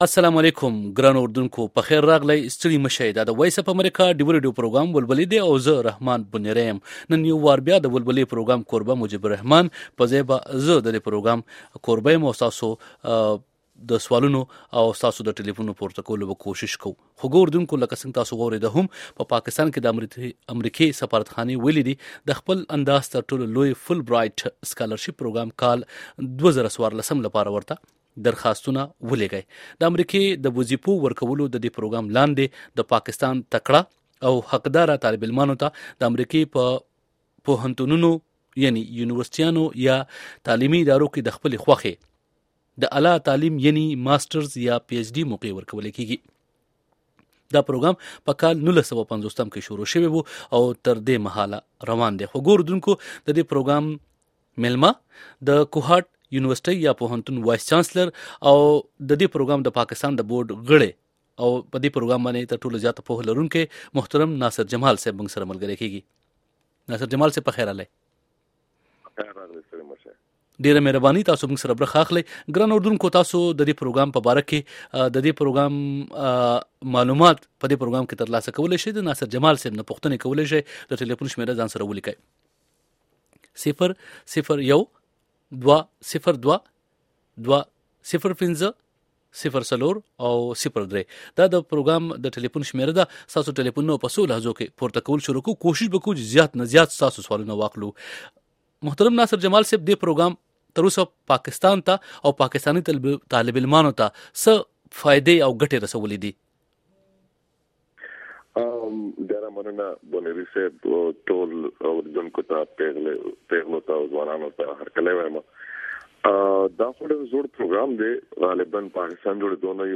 Deewa Radio Show on Fulbright Scholarship